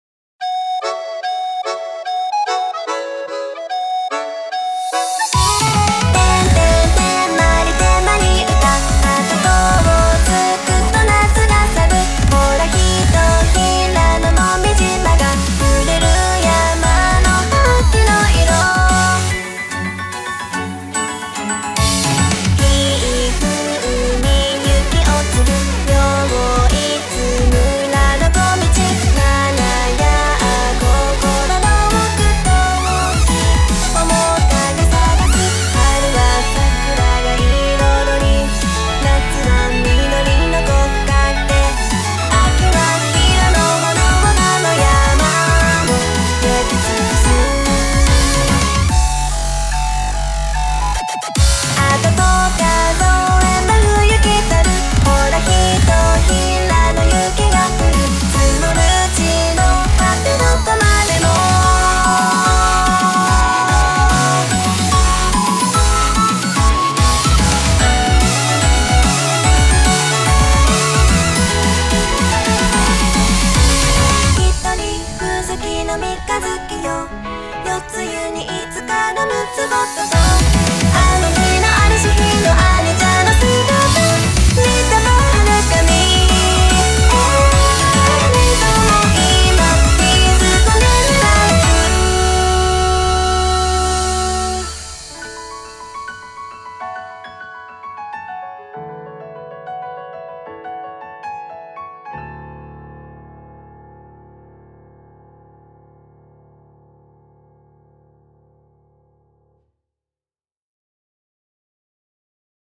BPM73-146
Audio QualityPerfect (High Quality)
Genre: WARABE STEP.